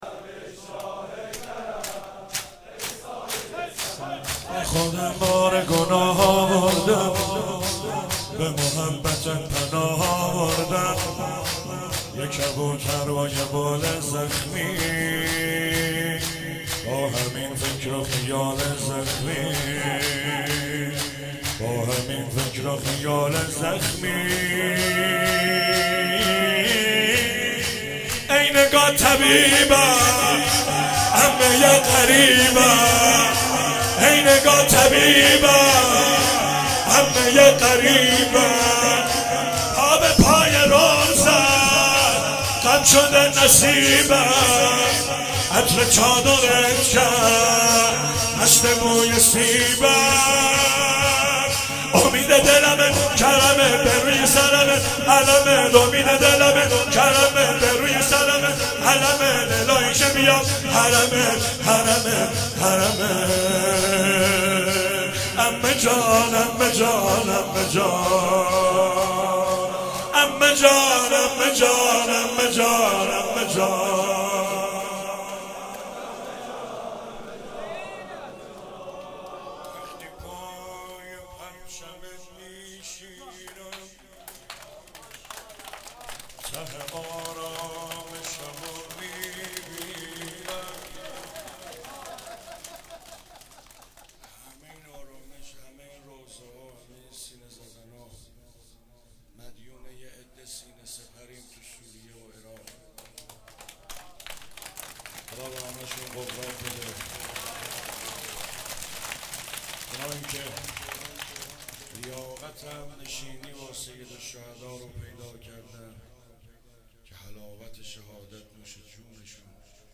شب چهارم محرم 96 - شور - با خودم بار گناه آوردم